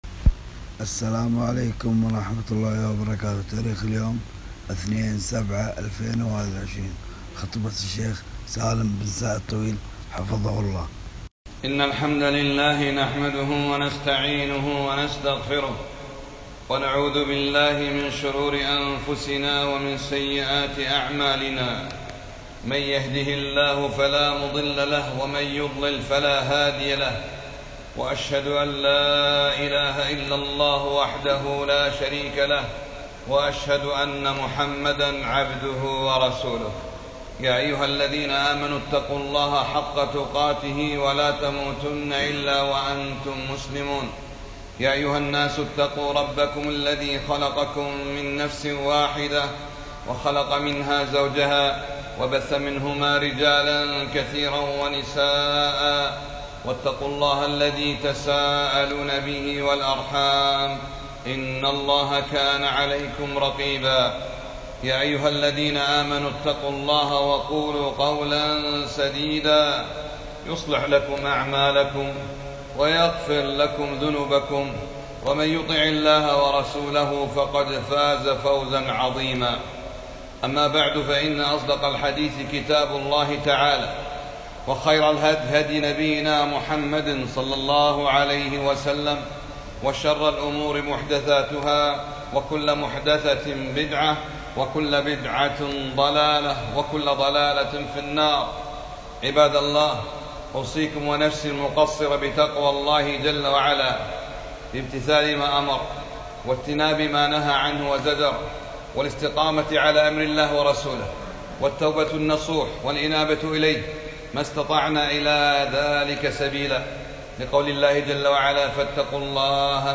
خطبة - توحيد الله بالعبادة (سفينة النجاة)